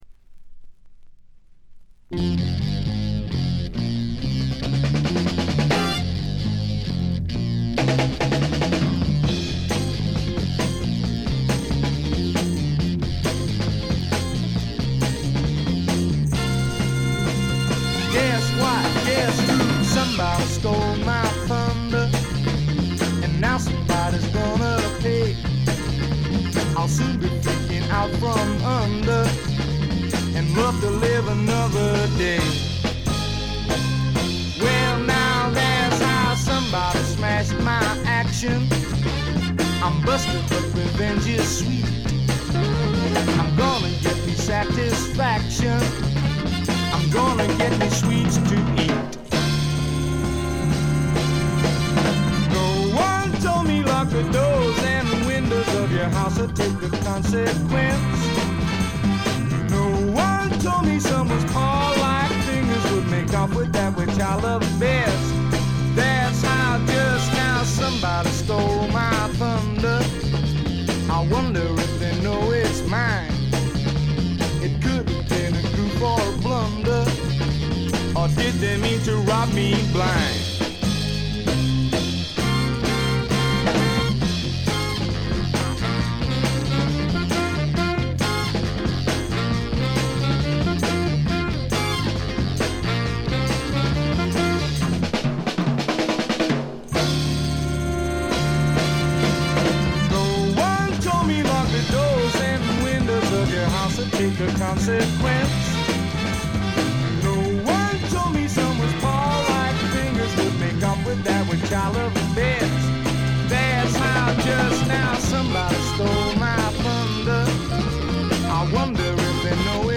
わずかなチリプチ。散発的なプツ音が数回。
試聴曲は現品からの取り込み音源です。